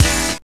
SWINGSTAB 3.wav